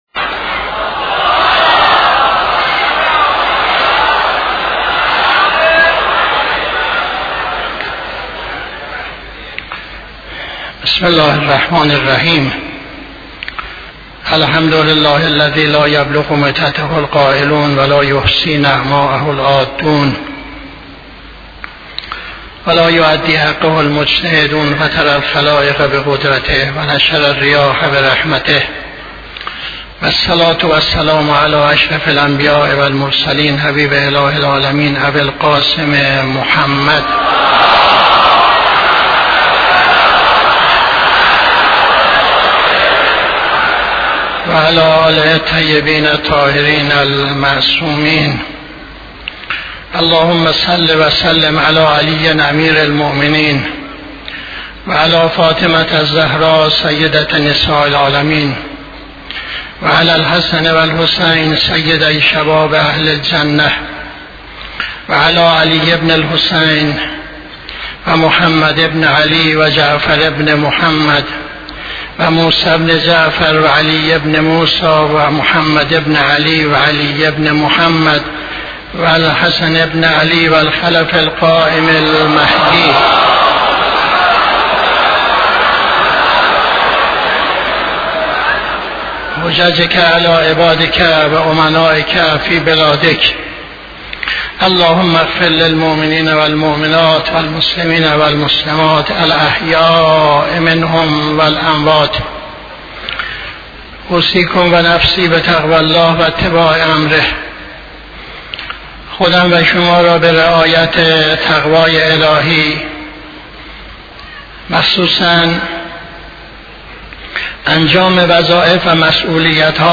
خطبه دوم نماز جمعه 05-11-80